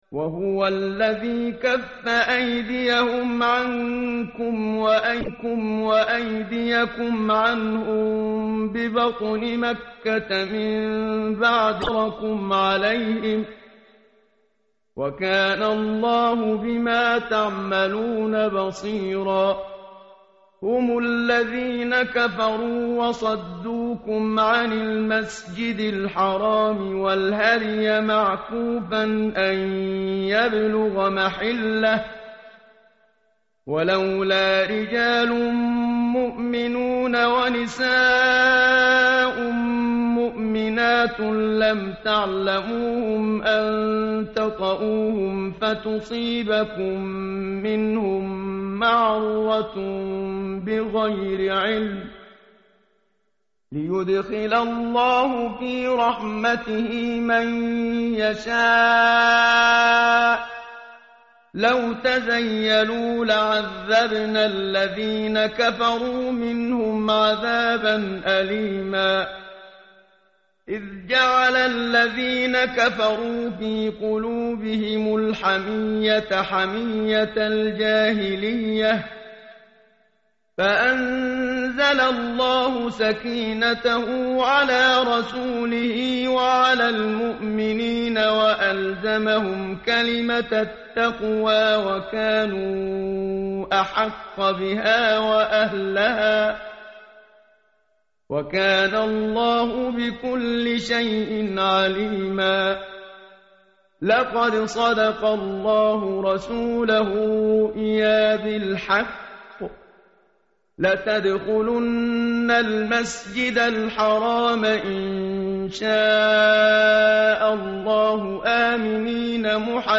متن قرآن همراه باتلاوت قرآن و ترجمه
tartil_menshavi_page_514.mp3